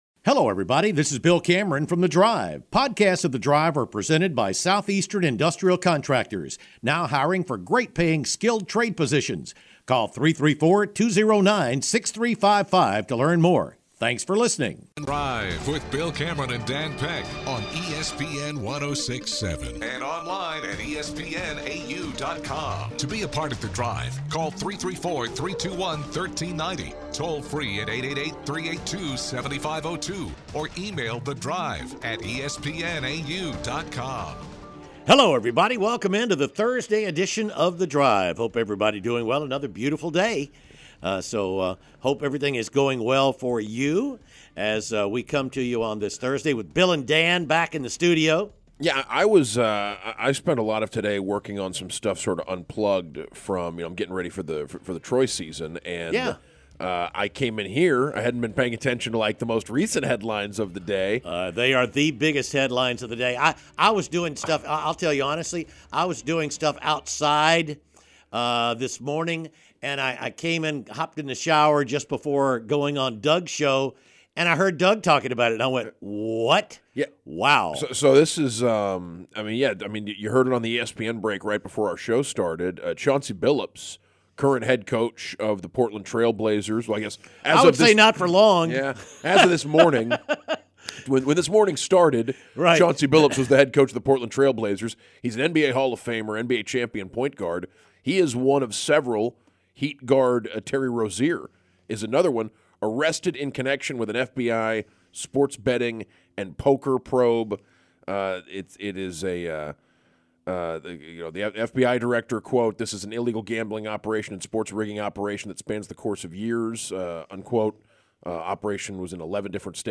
Callers wonder what might influence Auburn's decision to keep or dismiss Hugh Freeze.